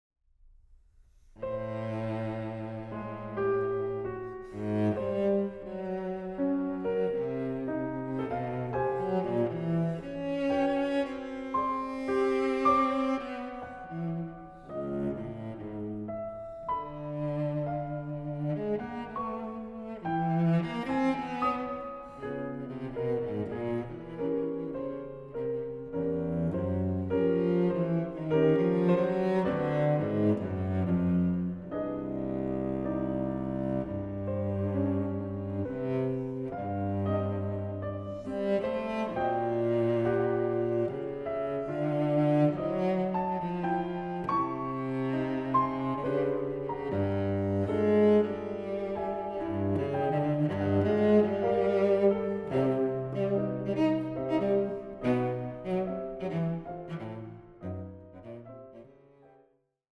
Aufnahme: Festeburgkirche Frankfurt, 2024
Piano Trio
I. Moderato